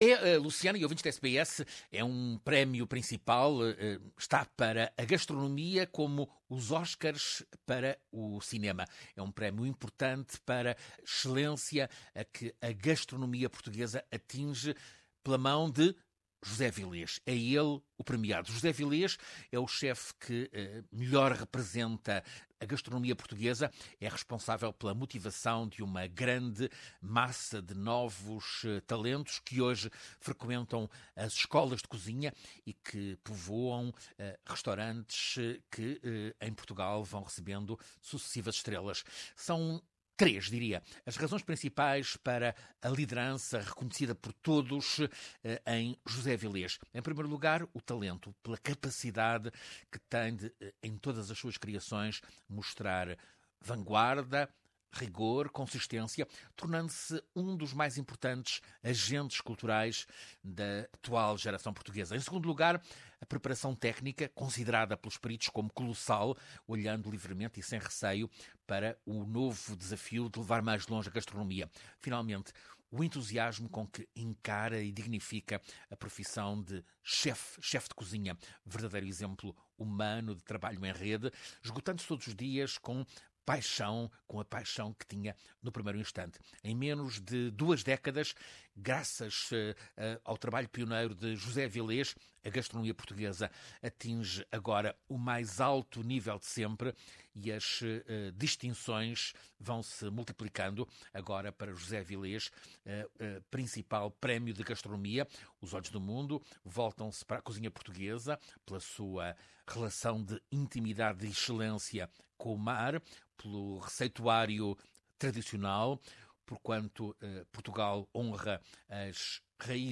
José Avillez é primeiro chef português a vencer o prestigiado prêmio da Academia Internacional da Gastronomia. Ouça reportagem